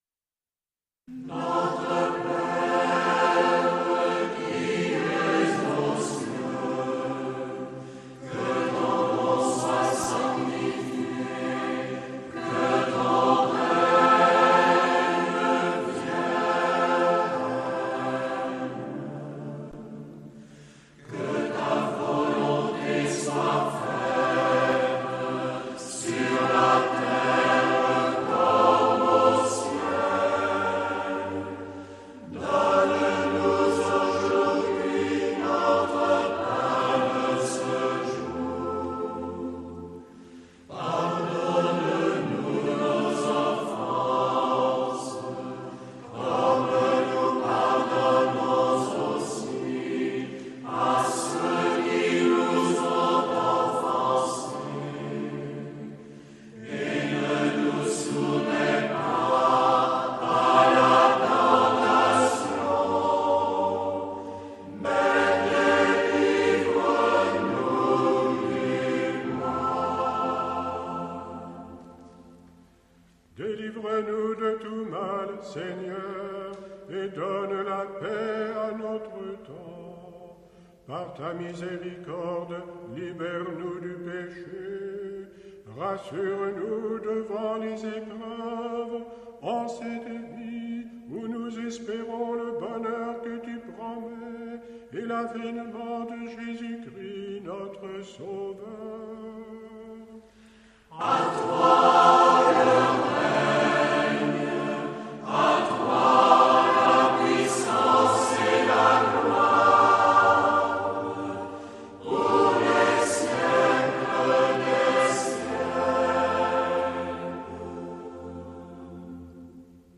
Nous pouvons aussi nous laisser porter par ce chant à notre père, D’après la prière de Charles de Foucauld